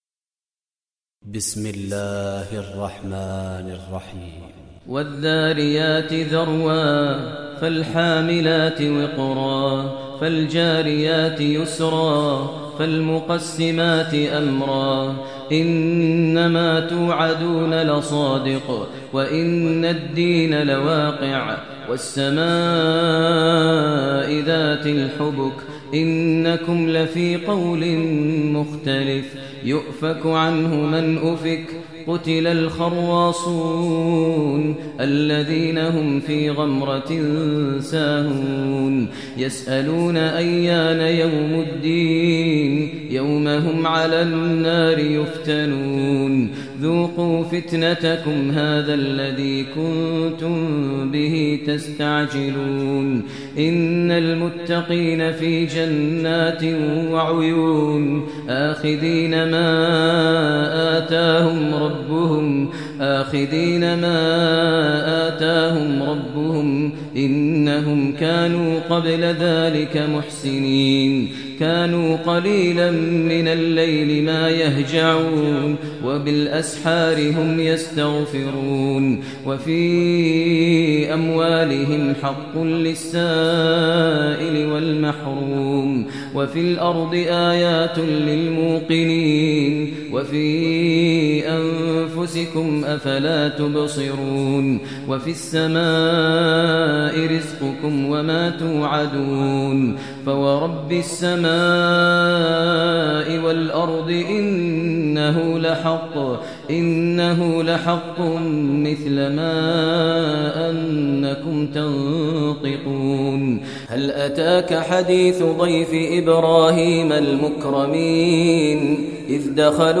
Surah Adh-Dhariyat Recitation by Maher al Mueaqly
Surah Adh-Dhariyat, listen online mp3 tilawat / recitation in Arabic recited by Sheikh Maher al Mueaqly.